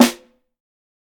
TC2 Snare 14.wav